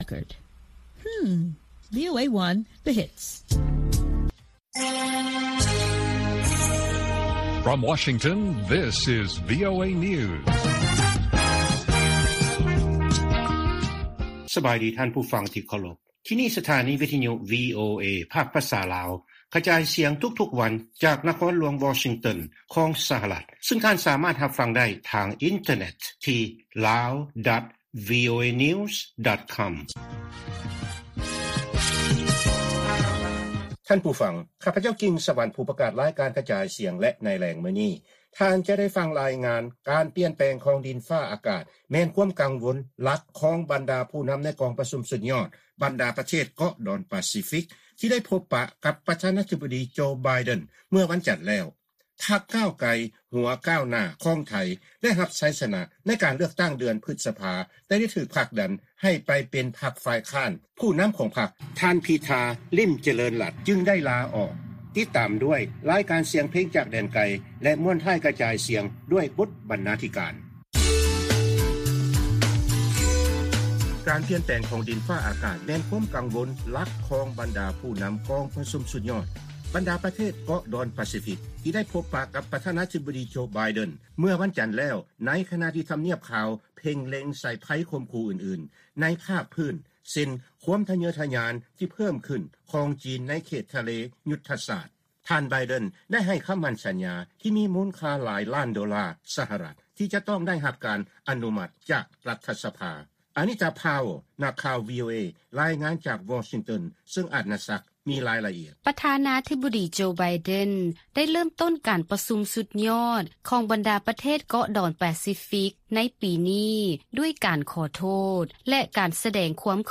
ລາຍການກະຈາຍສຽງຂອງວີໂອເອ ລາວ: ປ. ໄບເດັນ ປະກາດໃຫ້ການຊ່ວຍເຫຼືອ ດ້ານດິນຟ້າອາກາດ ແລະພື້ນຖານໂຄງລ່າງ ແກ່ບັນດາປະເທດໝູ່ເກາະປາຊີຟິກ